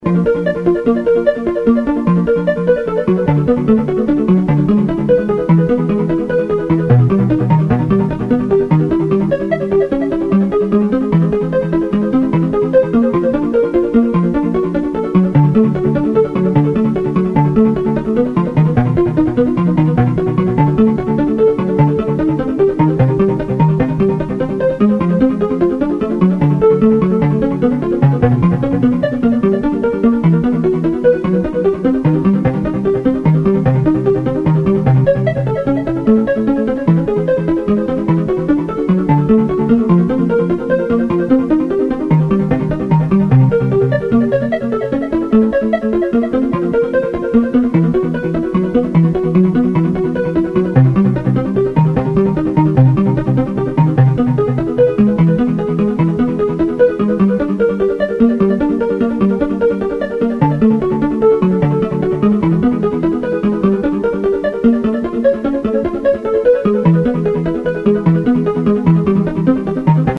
vuelo de sintetizador flotante
Todas las pistas están remasterizadas para la serie.